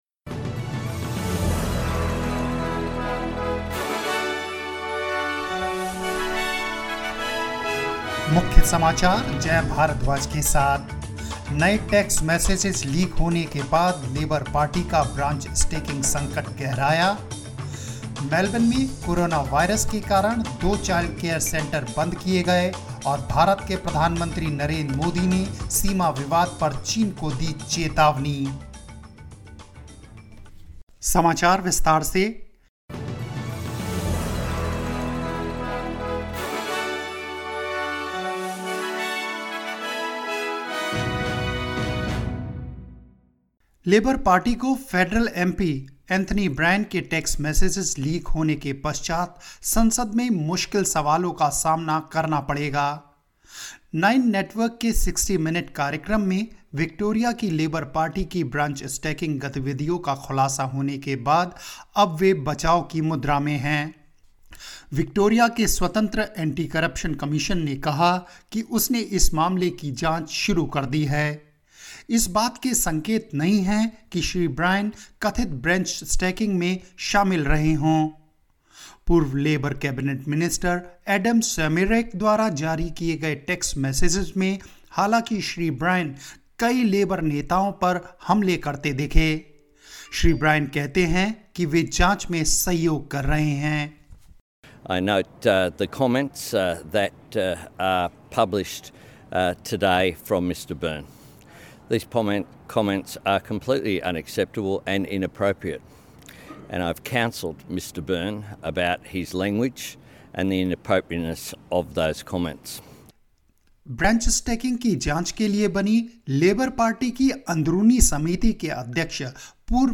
hindi_1806_news_and_headlines.mp3